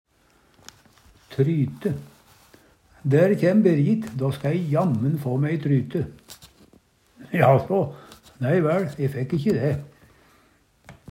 tryte - Numedalsmål (en-US)